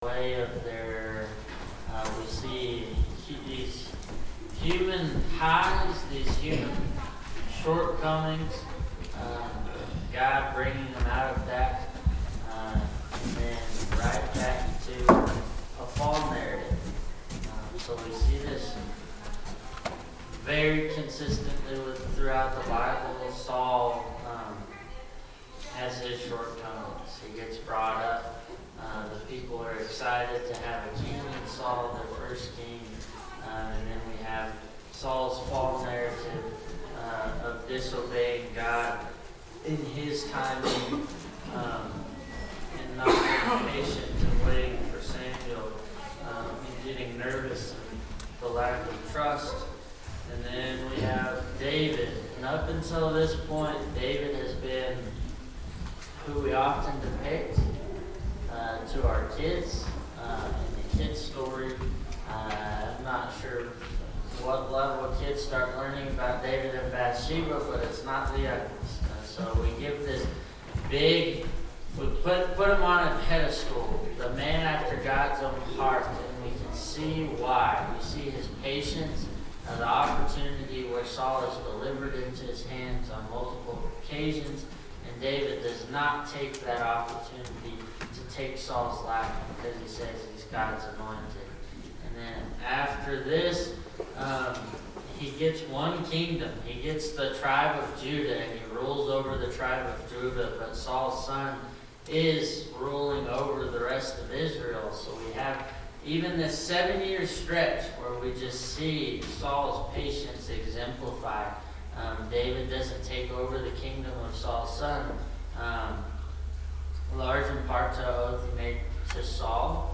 Bible Class 04/13/2025 - Bayfield church of Christ